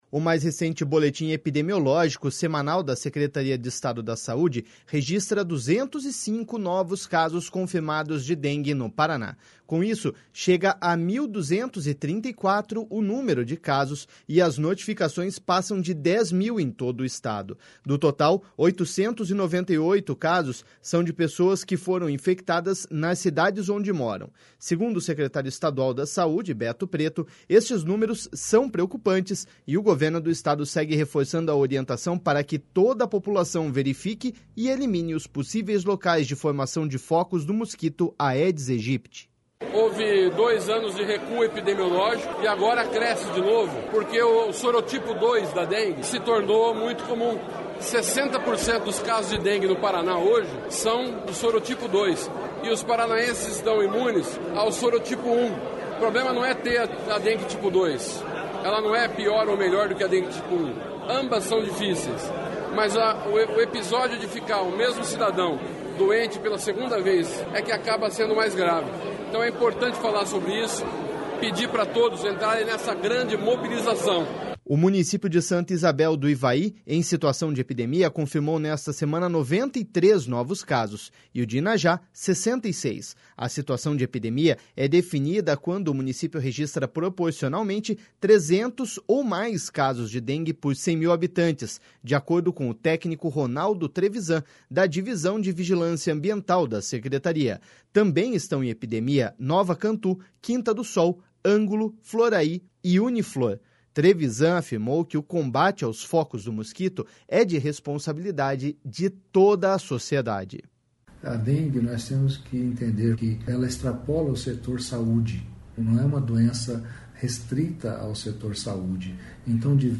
Segundo o secretário estadual da Saúde, Beto Preto, estes números são preocupantes, e o Governo do Estado segue reforçando a orientação para que toda a população verifique e elimine os possíveis locais de formação de focos do mosquito Aedes Aegypti.// SONORA BETO PRETO.//